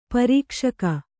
♪ parīkṣaka